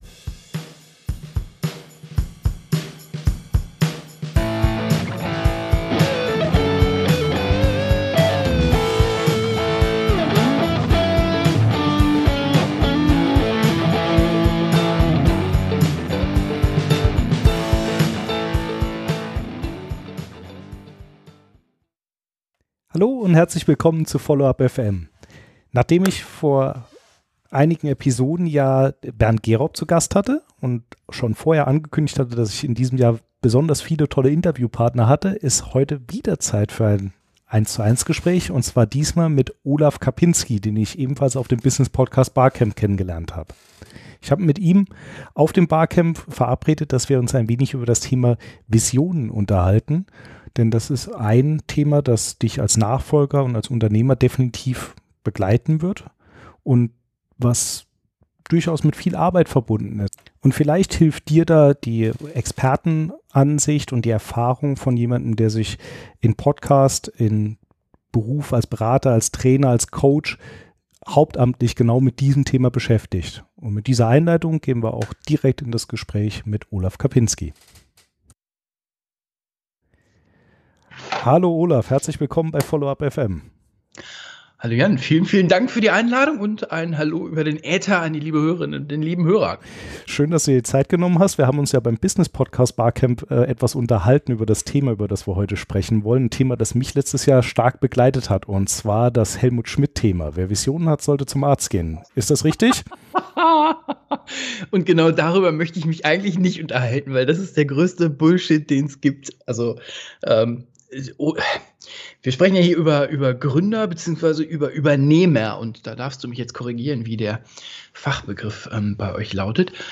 FUFM026: Die Vision als Nachfolger - Im Gespräch